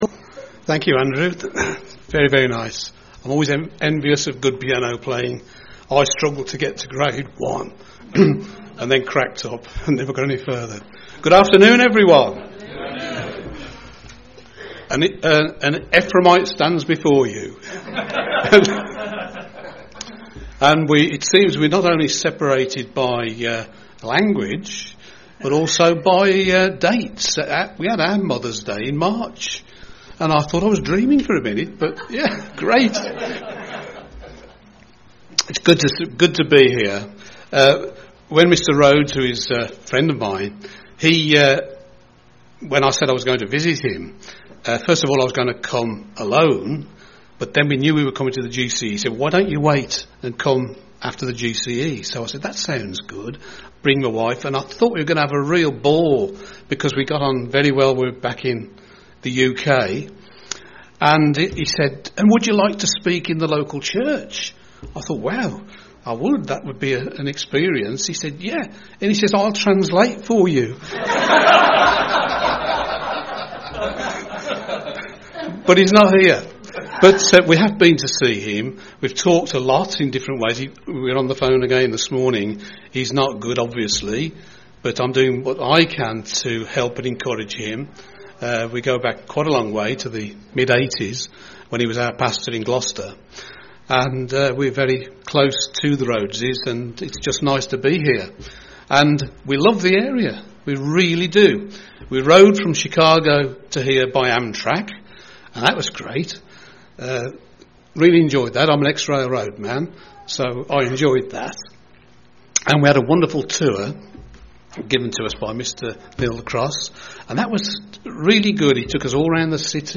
Given in Lansing, MI
UCG Sermon Studying the bible?